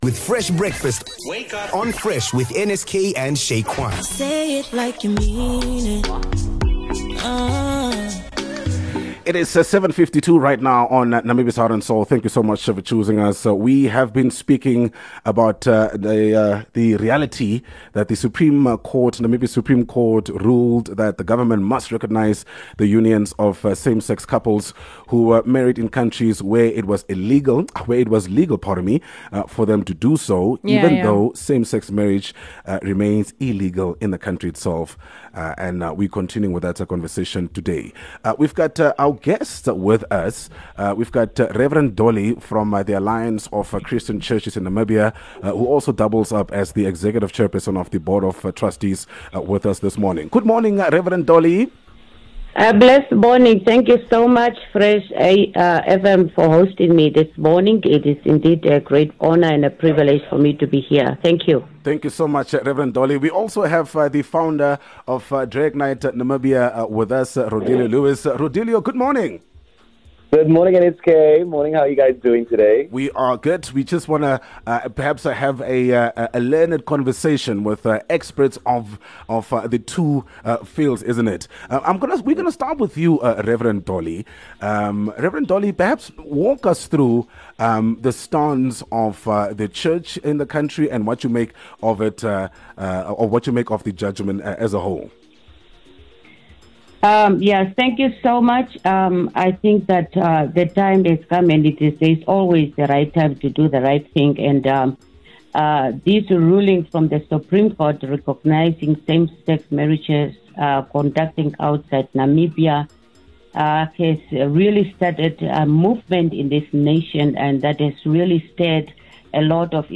round table discussion